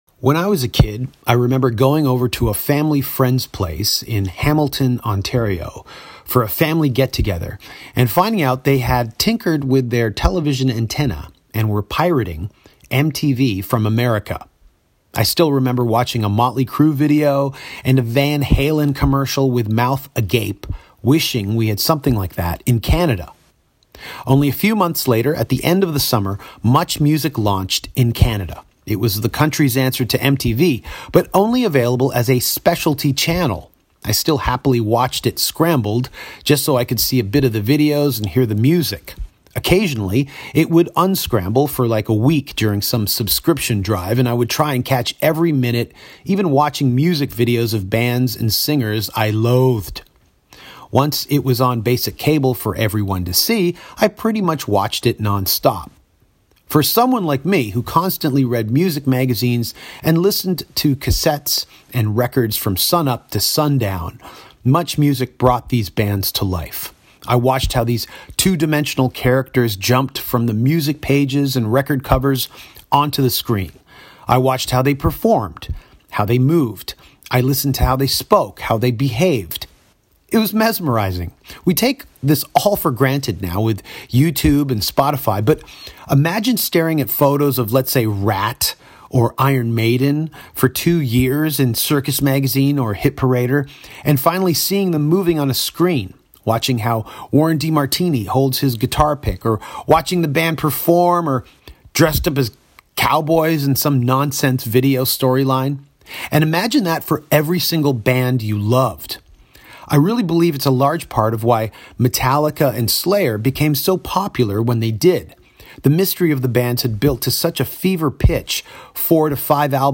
Danko talked to Christopher Ward about his book, Is This Live?, the oral history book on MuchMusic and his time as a MuchMusic VJ, his Famous Lost Words podcast, Elvis Costello, Elton John, David Lee Roth, Eddie Van Halen and …